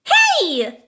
daisy_attacked_2.ogg